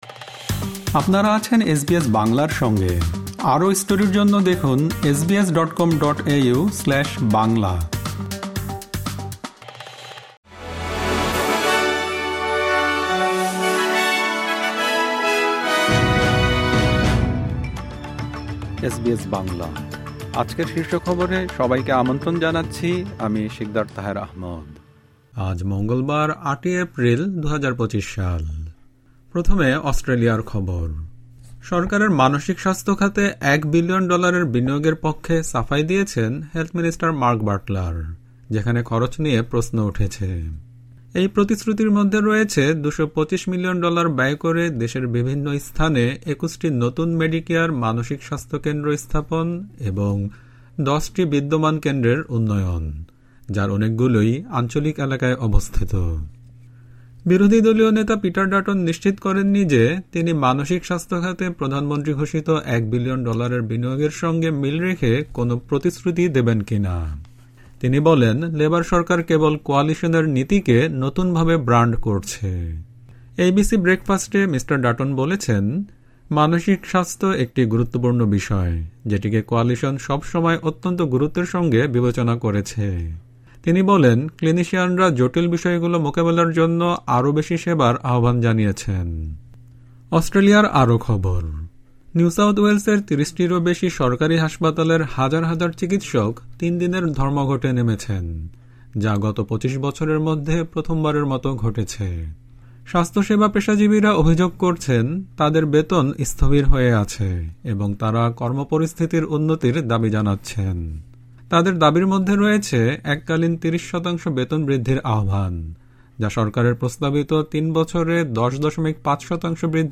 এসবিএস বাংলা শীর্ষ খবর: ৮ এপ্রিল, ২০২৫